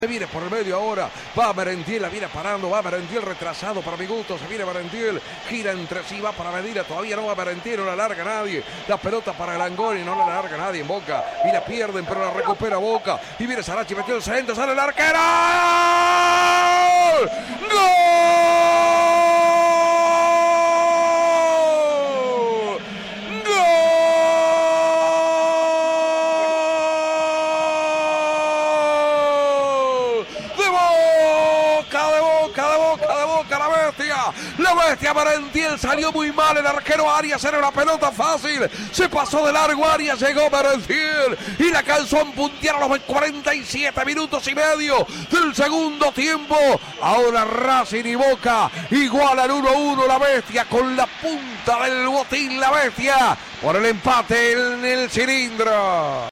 relató los goles del clásico